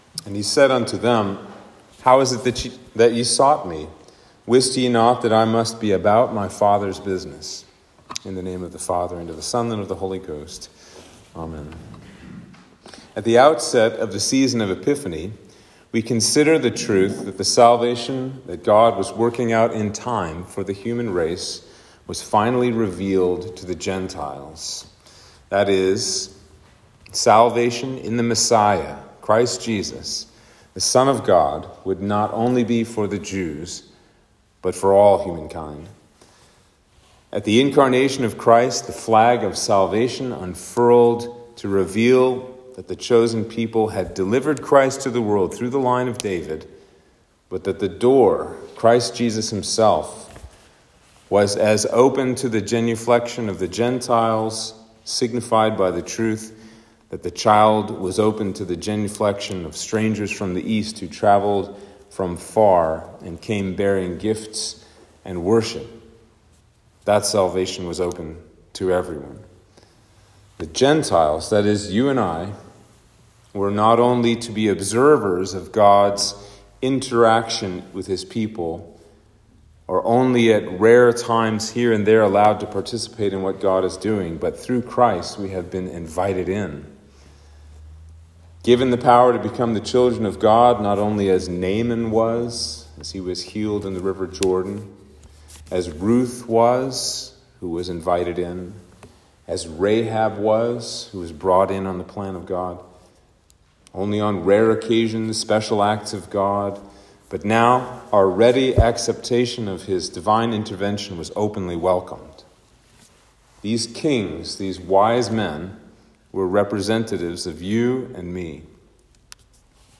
Sermon for Epiphany 1